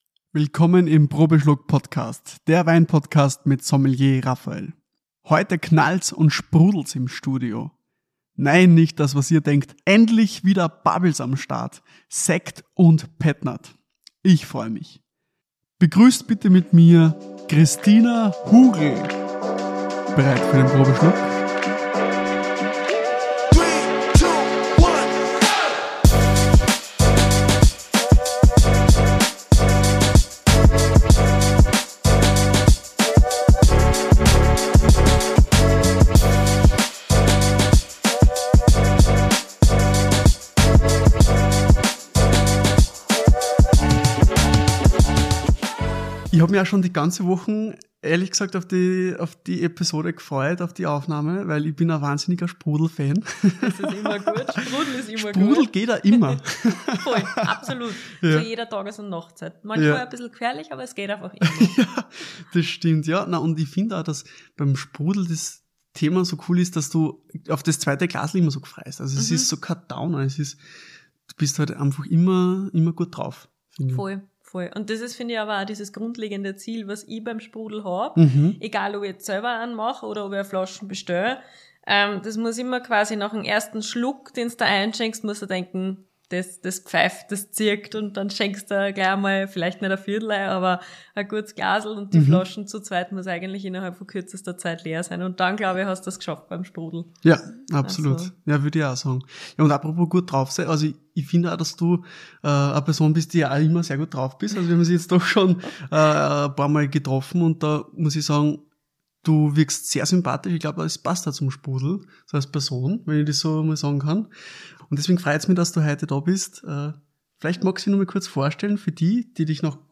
Achtung, es sprudelt im Probeschluck Podcast Studio!